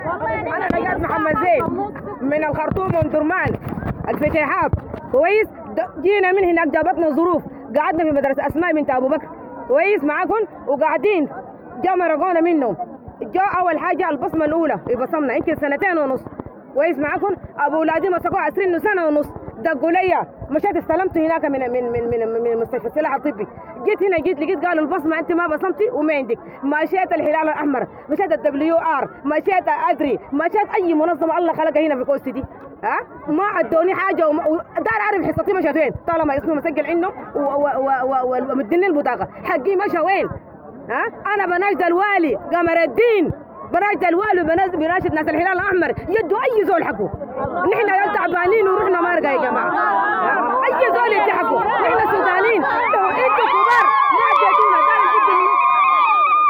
امرأة-من-المعسكر.mp3